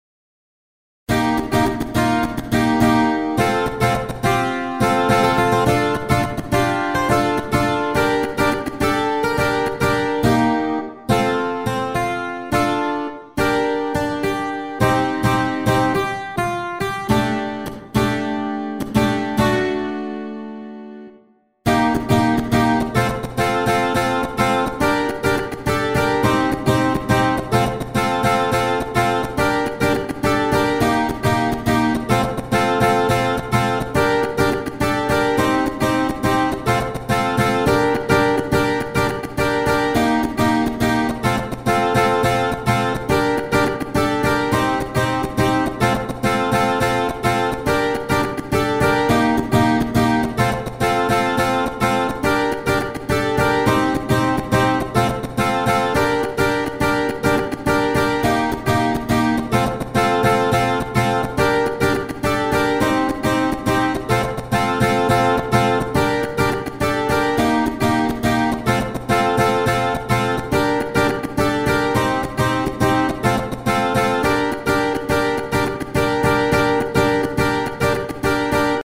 HALion6 : A.Guitar
Electoro-Acoustic Guitar